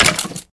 骷髅：受伤
受伤时随机播放这些音效
Minecraft_Skeleton_hurt4.mp3